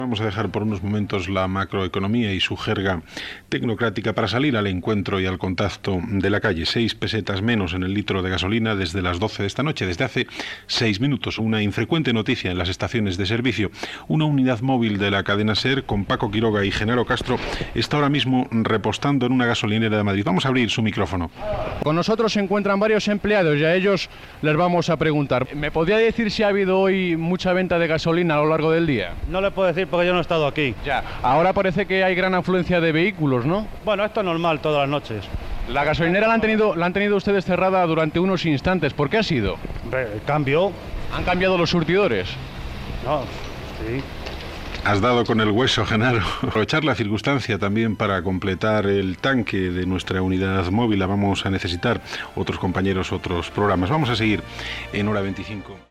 Informació (a les 00:06 hores del dia 13) de la baixada de preus de la benzina i conexiò amb la unitat mòbil que està en una benzinera de Madrid
Informatiu